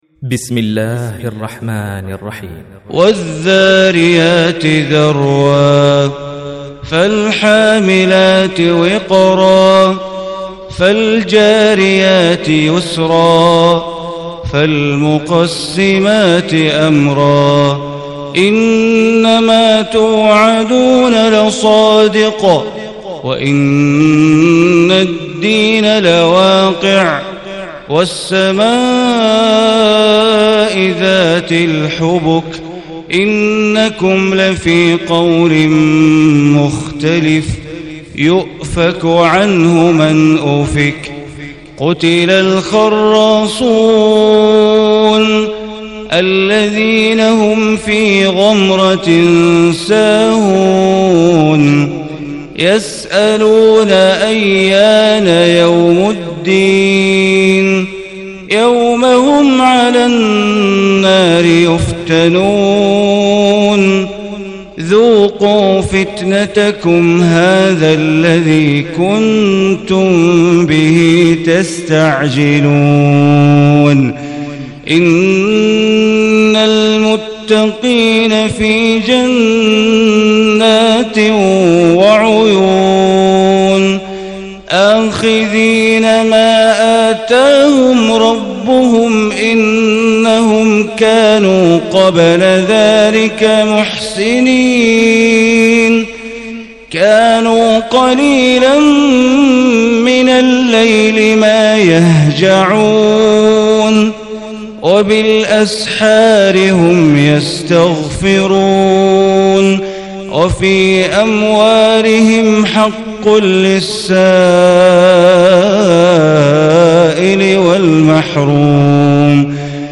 Surah Adh-Dhariyat Recitation by Bandar Baleela
Surah Dhariyat, is 51 surah of holy Quran. Listen online or download mp3 tilawat / recitation in Arabic in the beautiful voice of Sheikh Bandar Baleela.